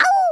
Worms speechbanks
ow1.wav